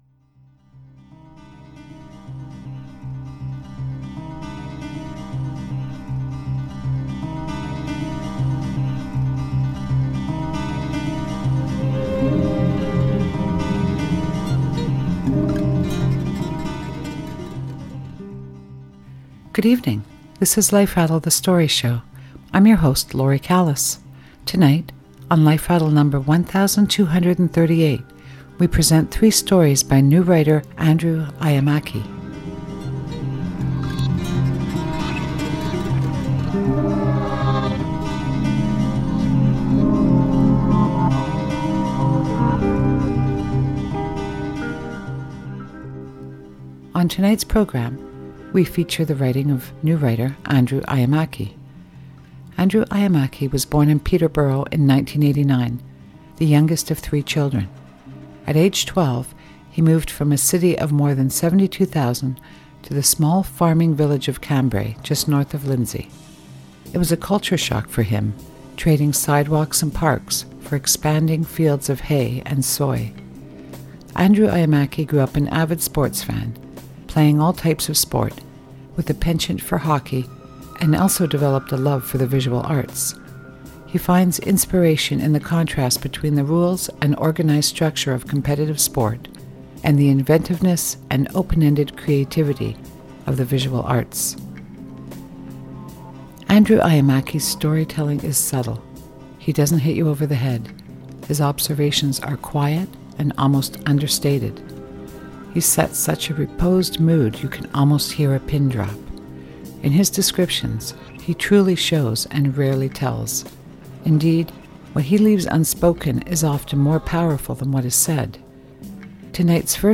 Tonight’s first and second story are both told from the perspective of a child. In the first, a little boy watches his mother get ready for work as he also prepares himself to watch his favourite Saturday morning cartoon. In the second, a boy unhappily visits his grandpa’s house where his father now lives. In our final story, our narrator is now a young man who anxiously waits for his girlfriend to arrive after work.